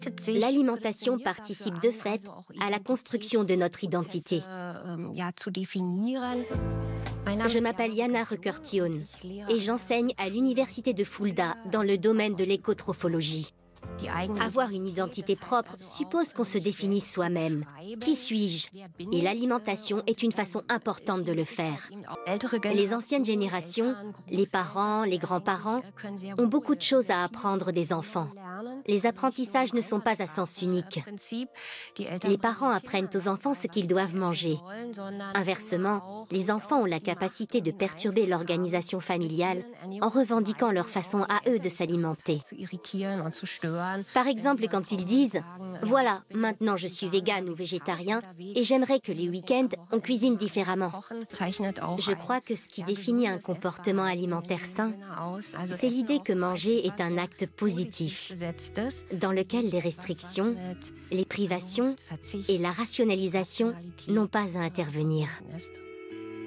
voix femme documentaire Arte
J'ai une voix jeune, légère, cristalline et pétillante...